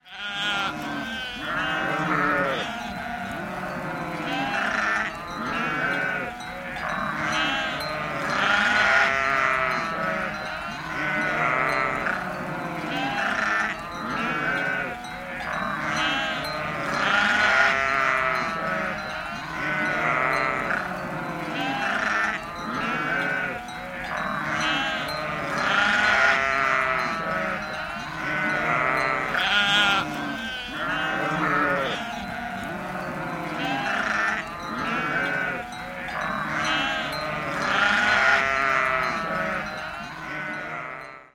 Звуки скотного двора
Животные беспокоятся